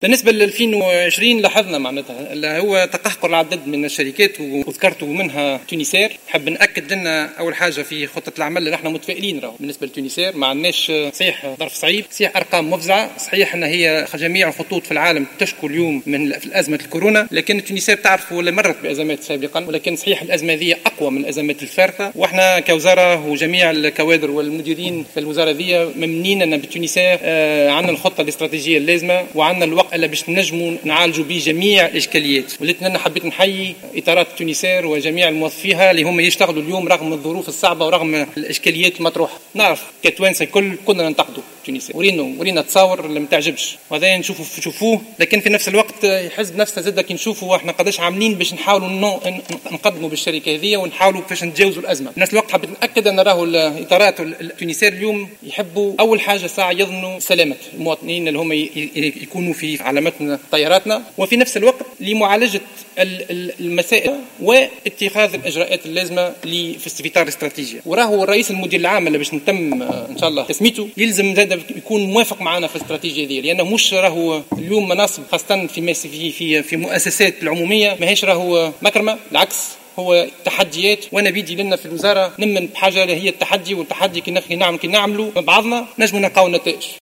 وبين الوزير خلال الجلسة العامة المخصصة لعرض مهمة وزارة النقل، وفي إجابة على انتقادات أعضاء مجلس نواب الشعب، أن سنة 2020 شهد تقهقر عدد من الشركات منها "التونسار".